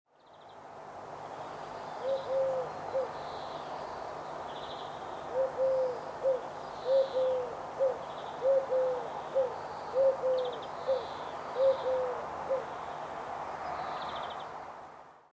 Uhu Ruf
Uhu-Geraeusche-Voegel-in-Europa.mp3